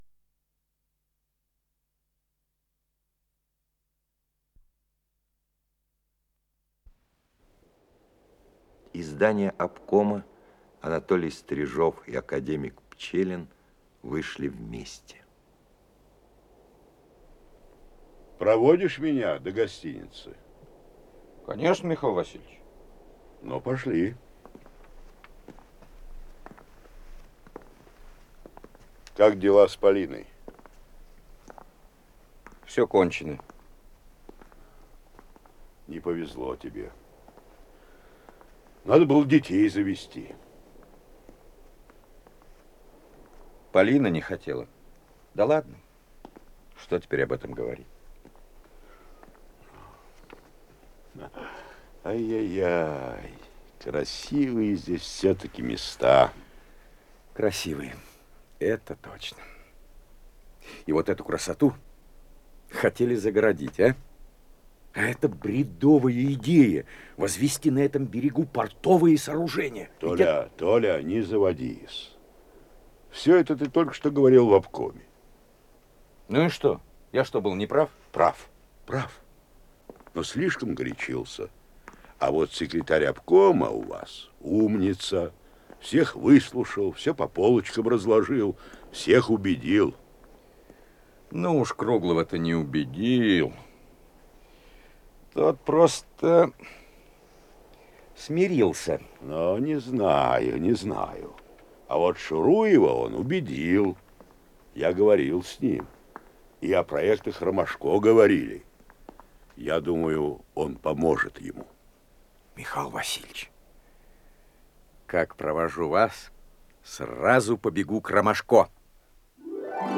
Радиопостановка, 3-я часть